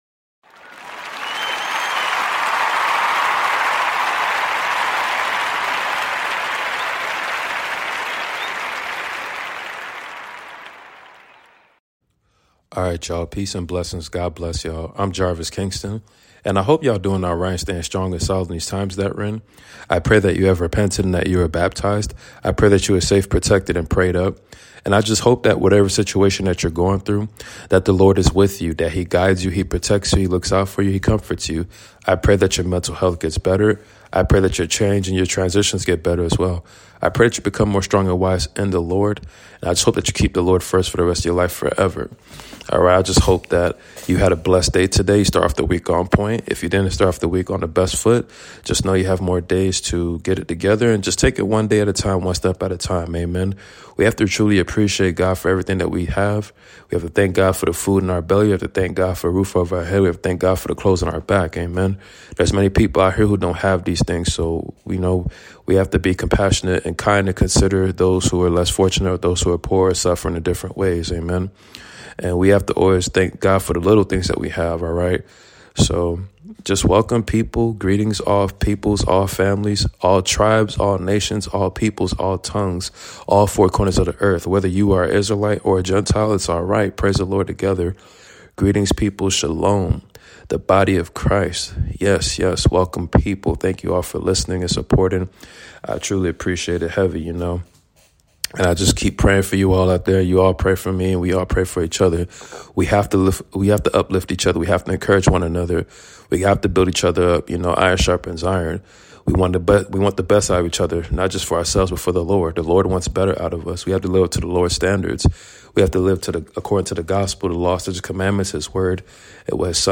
Book of 1st Samuel Chapter 3-7 reading 📖 Bible study! Pray